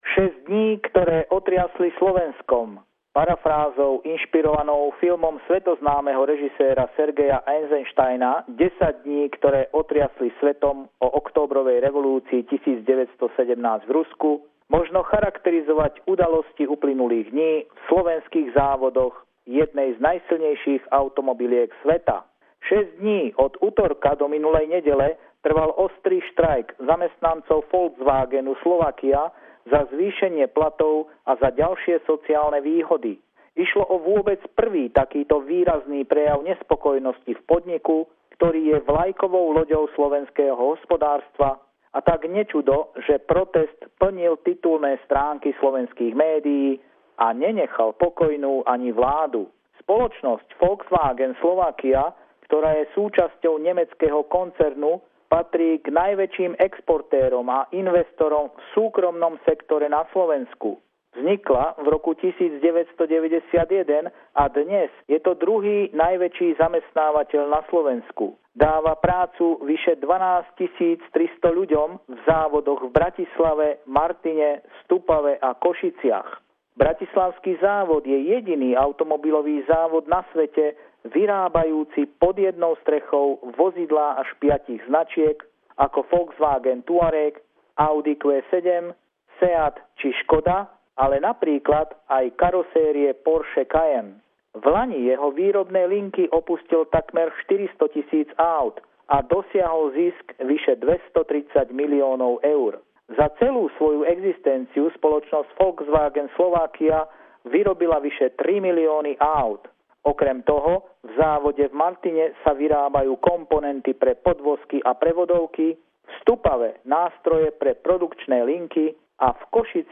Pravidelný telefonát týždňa